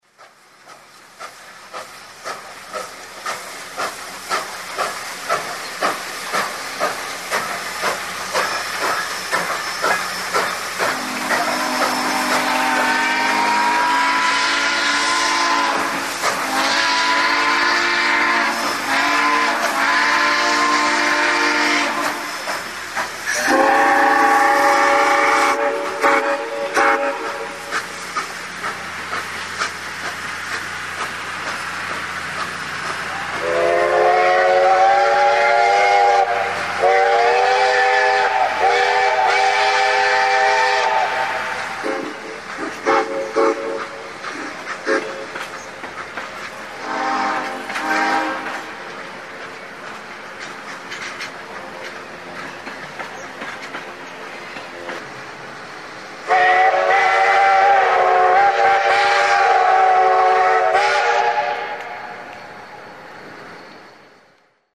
Dusk is quickly turning to night as the stately 4-6-2 does a slow march past Santa Clara Tower and a quietly steaming 4449, its mars light swinging wildly six tracks over. As the small crowd of onlookers stands gawking at the sight and sound of these two veteran steamers whistling off at each other, the Santa Clara Tower OS is busy getting ready for her next train movement.
SANTA CLARA LIVE!